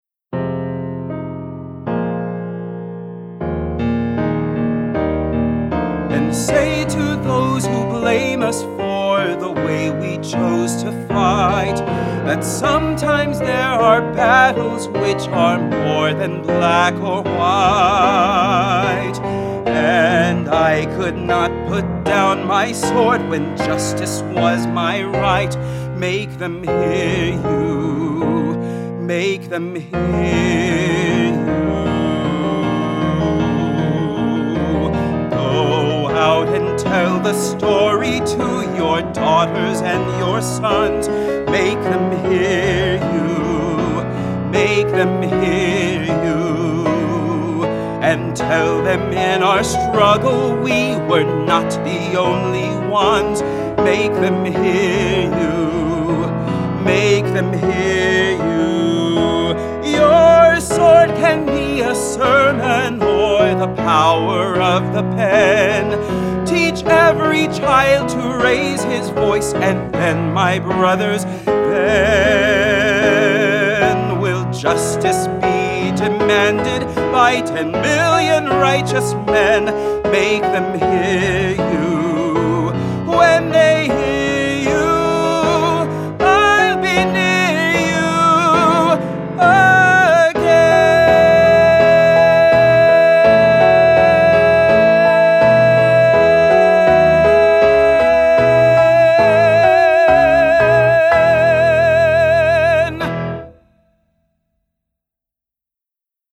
musical theatre song
Baritone/Bass Track